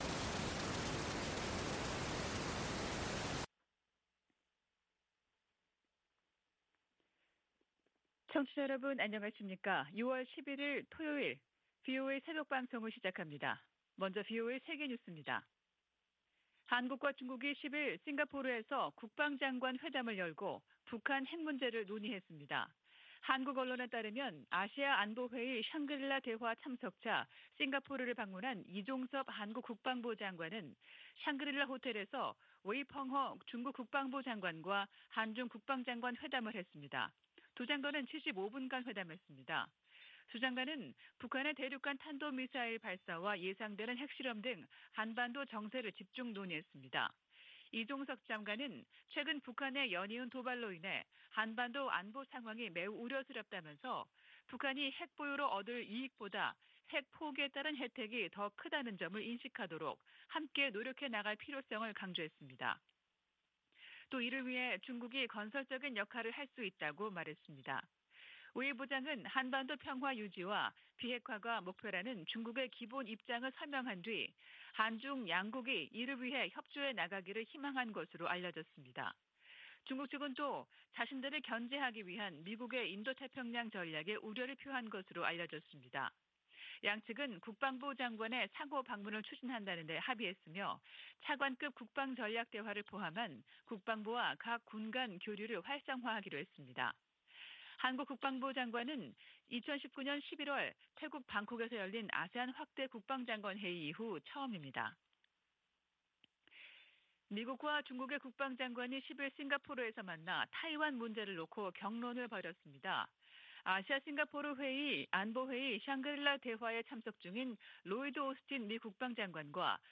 VOA 한국어 '출발 뉴스 쇼', 2022년 6월 11일 방송입니다. 윤석열 한국 대통령이 한국 정상으로는 처음 나토 정상회의에 참석합니다. 북한이 핵실험을 강행하면 억지력 강화, 정보유입 확대 등 체감할수 있는 대응을 해야 한다고 전직 미국 관리들이 촉구했습니다.